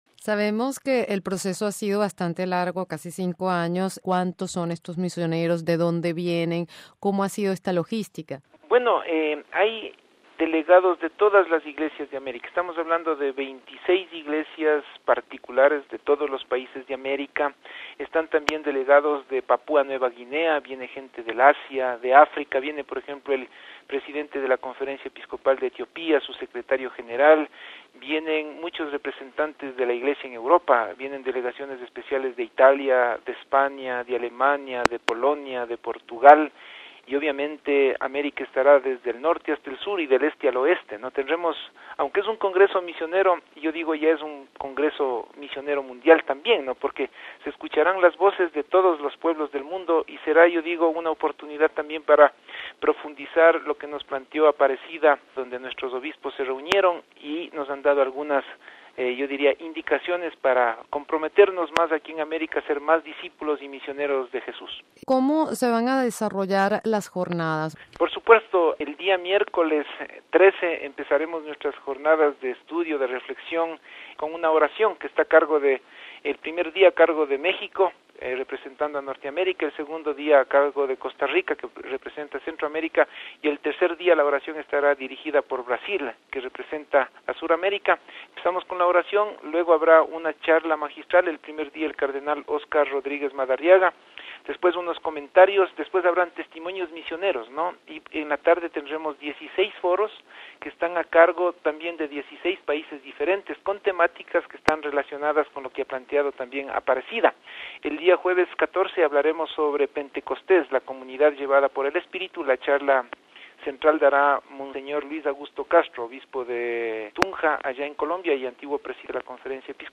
ha conversado